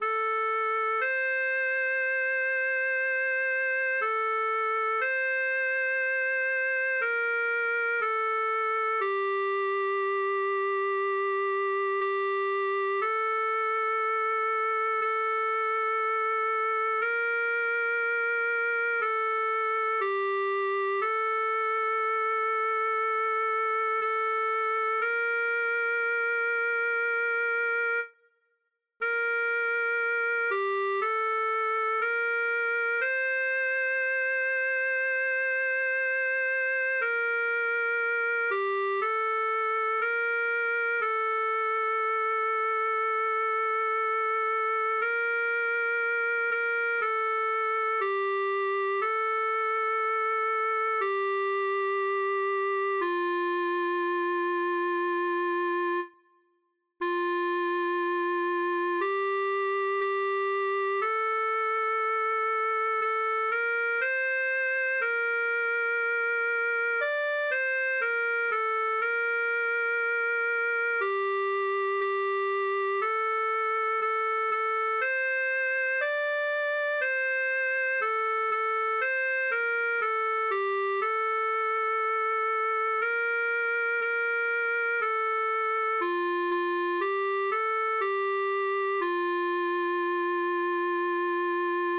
Voci (mp3): sopran, alto, tenor, bas, cor mixt